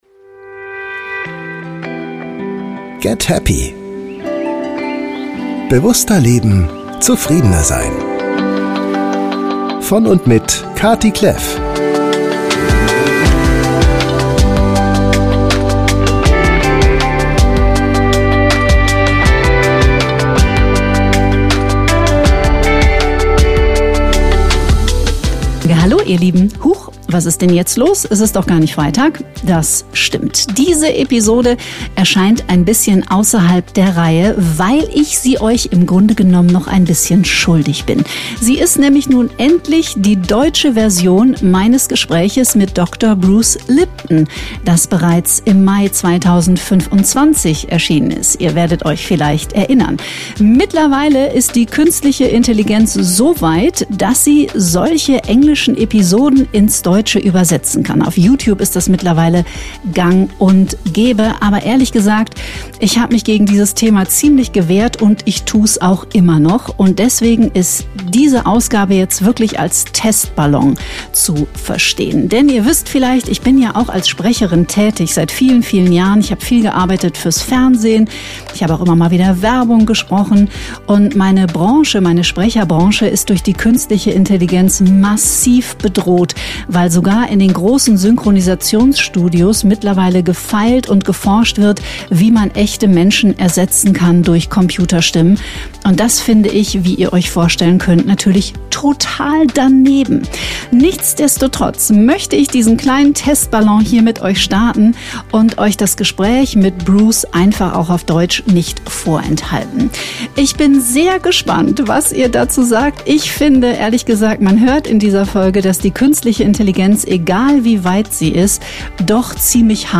Dies ist die KI- generierte deutsche Übersetzung der Folge mit Bruce Lipton aus dem März 2025.